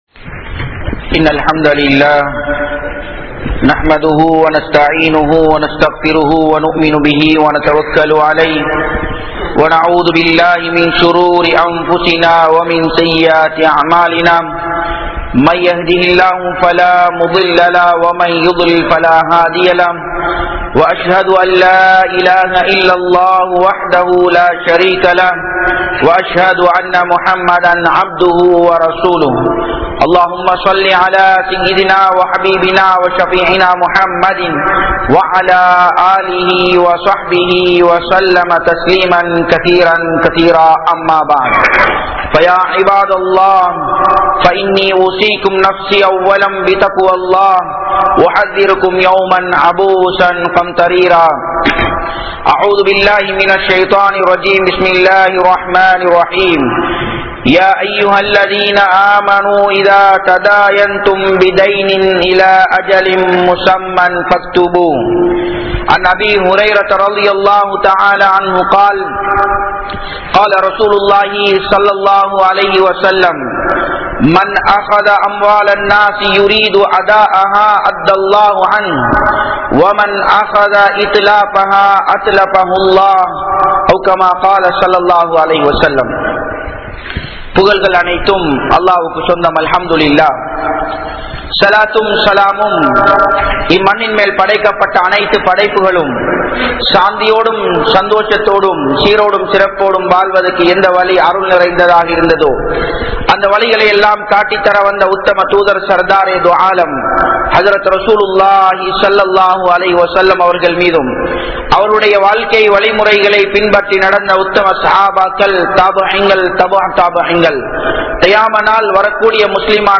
Islamiya Paarvaiel Kadan (இஸ்லாமிய பார்வையில் கடன்) | Audio Bayans | All Ceylon Muslim Youth Community | Addalaichenai